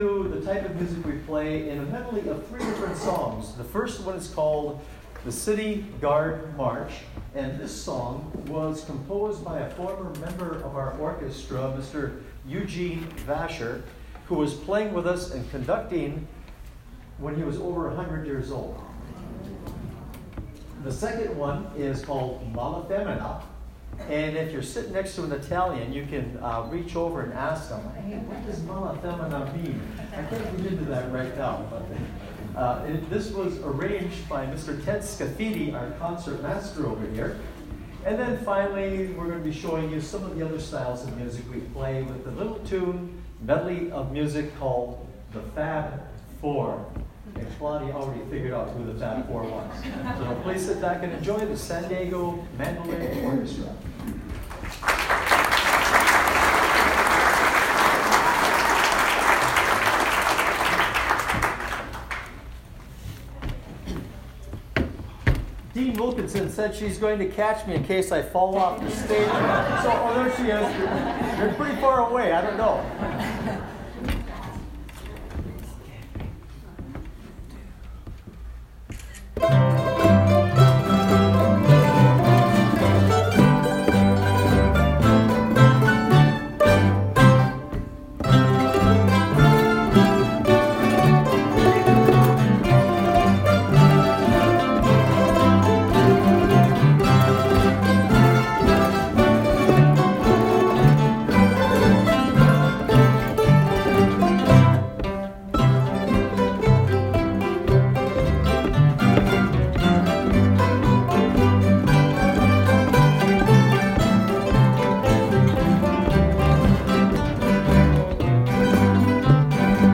Venezuelan Waltz by Carlos Bonnet Music Links Scripps Ranch Library Concert More links coming soon.